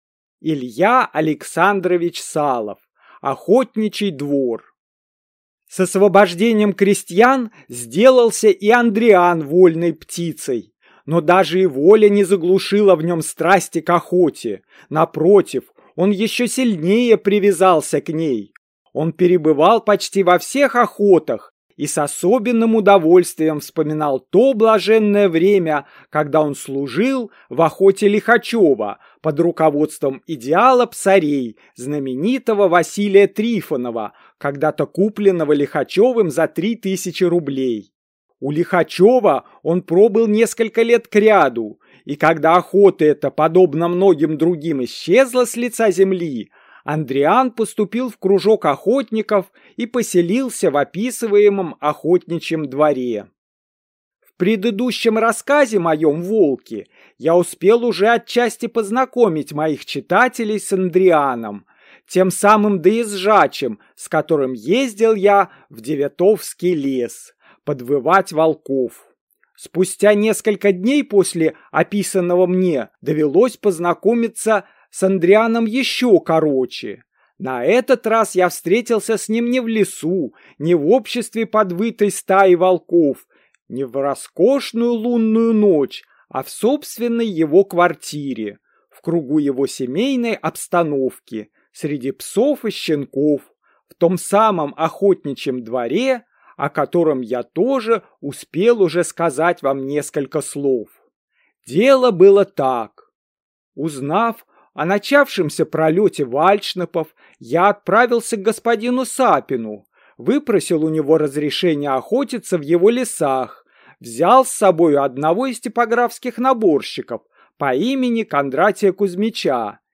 Аудиокнига Охотничий двор | Библиотека аудиокниг